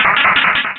Cri de Ludicolo dans Pokémon Rubis et Saphir.